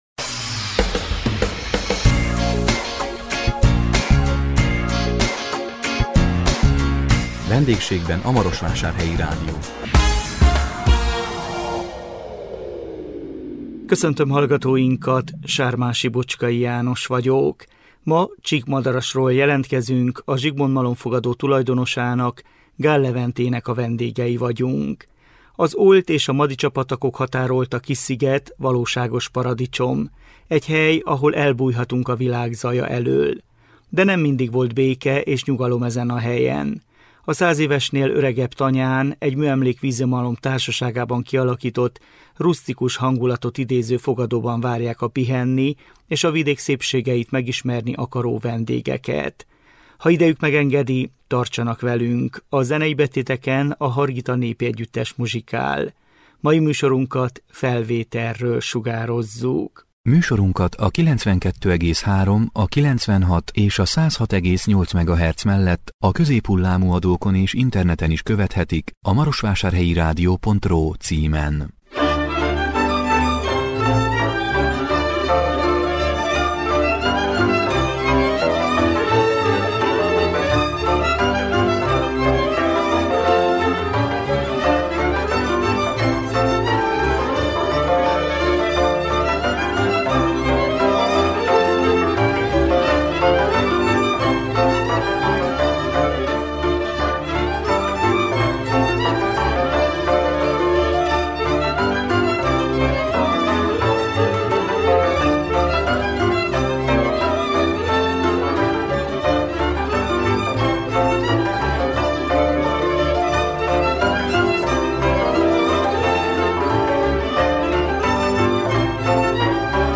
A 2025 március 13-án közvetített VENDÉGSÉGBEN A MAROSVÁSÁRHELYI RÁDIÓ című műsorunkkal Csíkmadarasról jelentkeztünk